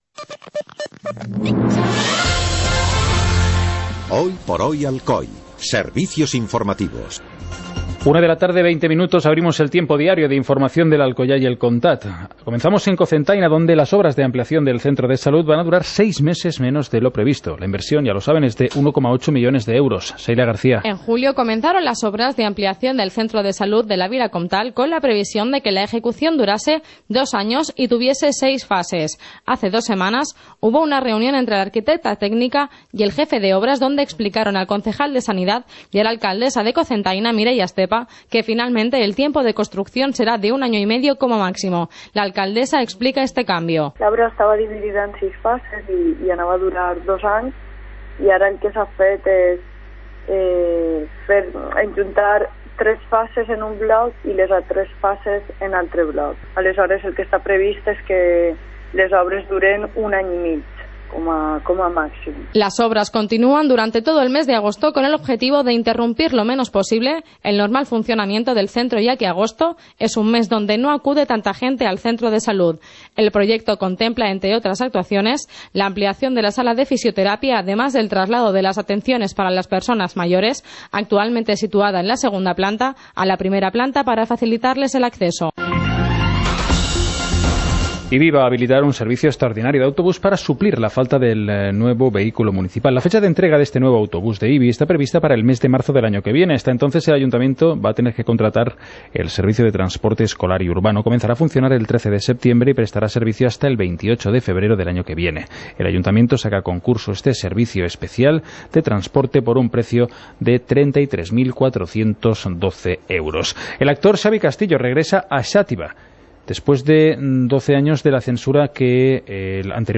Informativo comarcal - miércoles, 17 de agosto de 2016